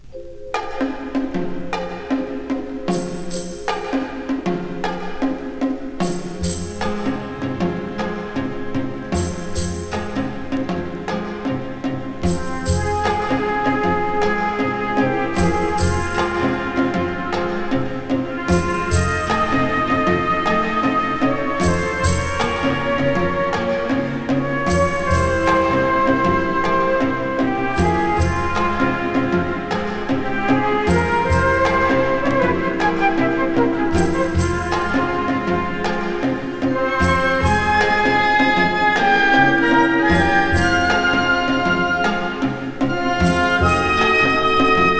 本盤で取り組んだのはキューバ音楽。